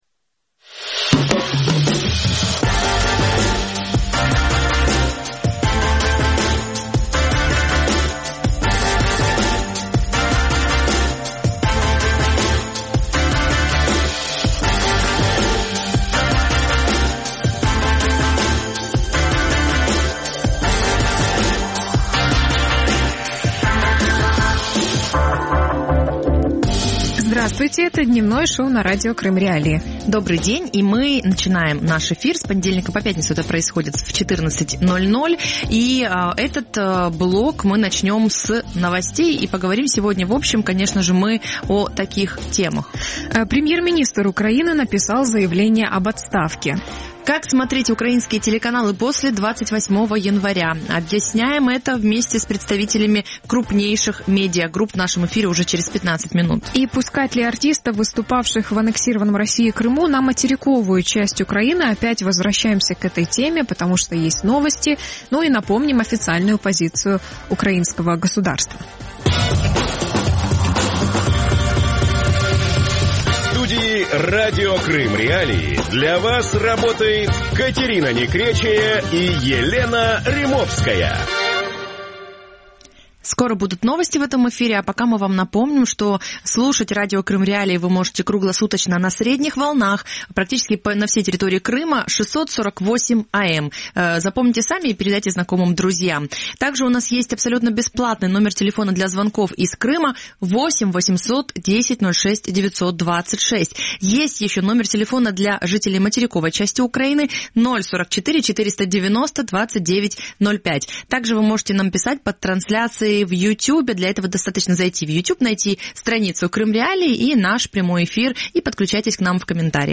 Как смотреть украинское ТВ в Крыму? | Дневное ток-шоу